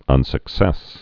(ŭnsək-sĕs)